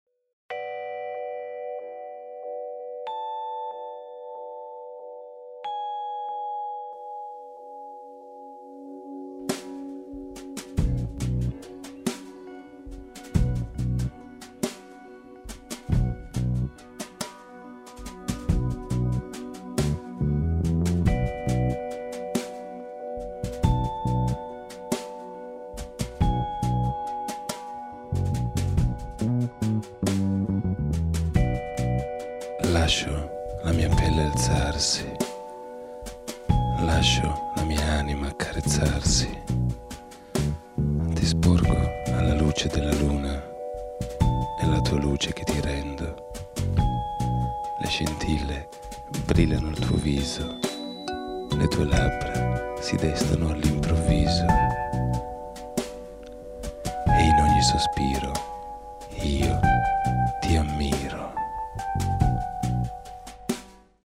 Sprecher italienisch.
Sprechprobe: Sonstiges (Muttersprache):
italien voice over talent